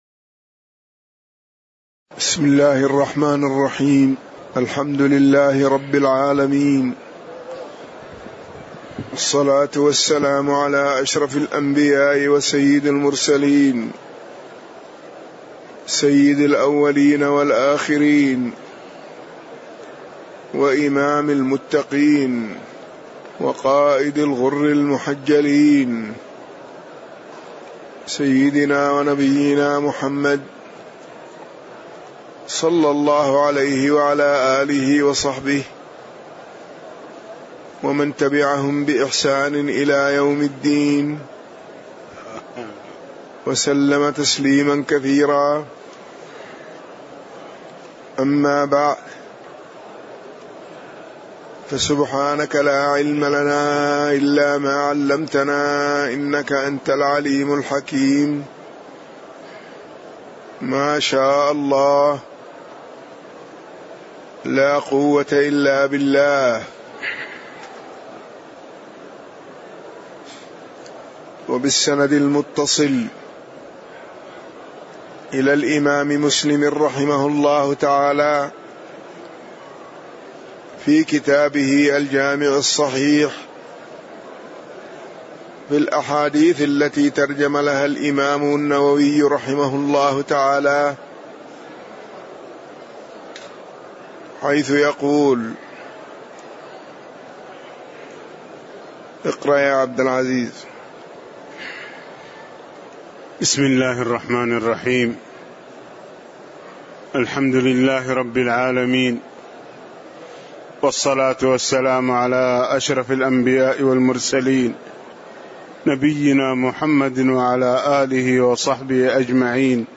تاريخ النشر ٣٠ محرم ١٤٣٨ هـ المكان: المسجد النبوي الشيخ